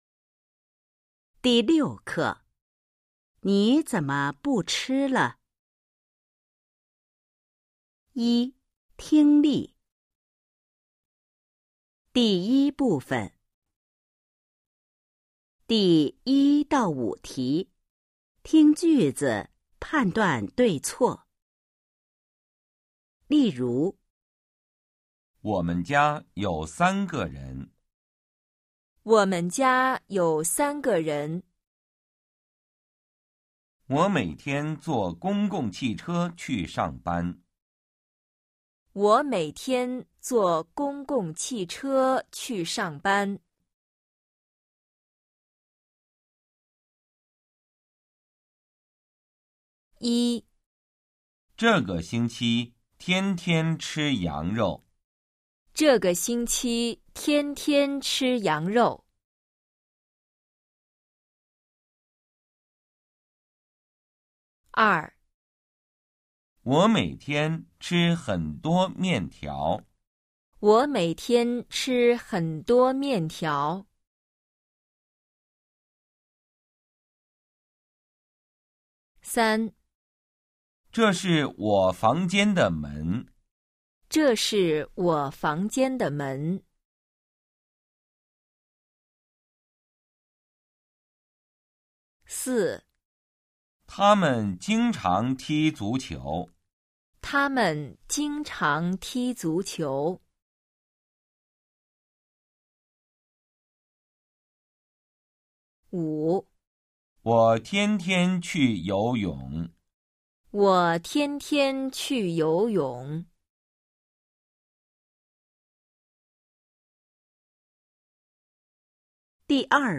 一、听力 Phần nghe 🎧 06-1